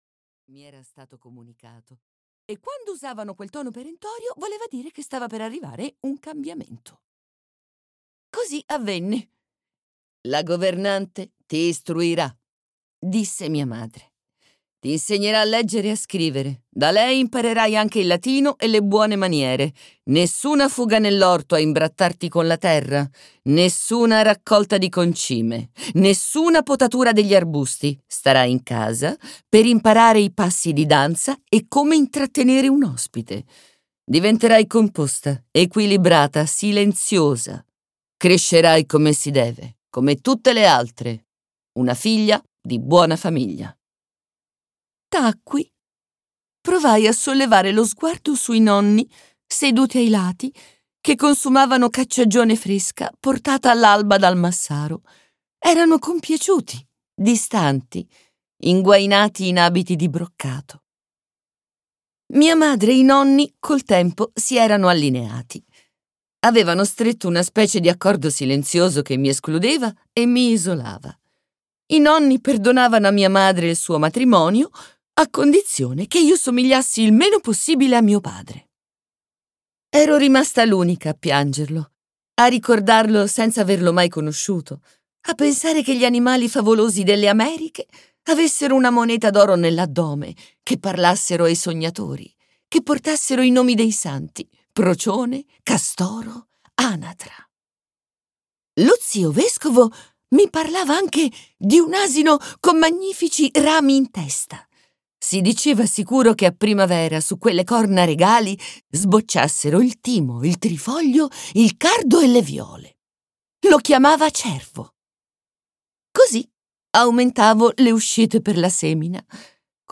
"Joanna degli incanti" di Simona Lo Iacono - Audiolibro digitale - AUDIOLIBRI LIQUIDI - Il Libraio